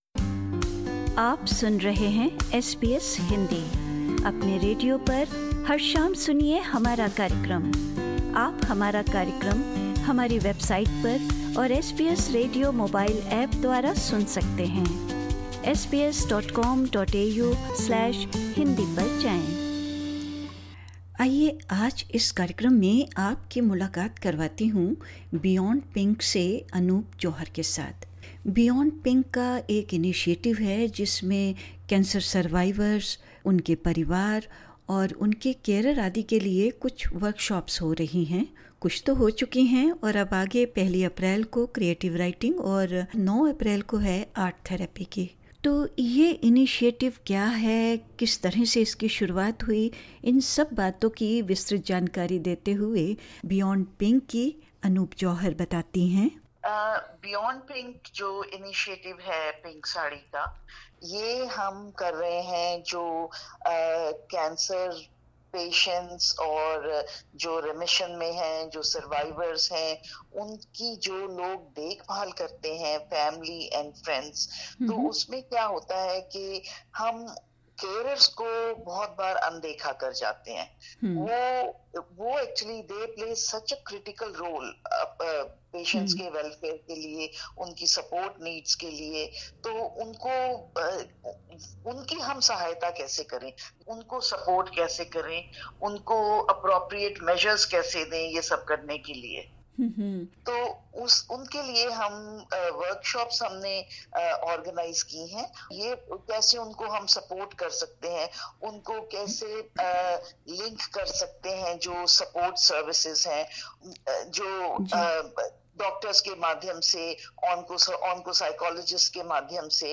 As an initiative of Pink Sari Inc in partnership with NSW Multicultural Health Communication Service, Beyond Pink aims to engage with the South Asian community who are affected by cancer, including their family, friends as well as carers. In an interview with SBS Hindi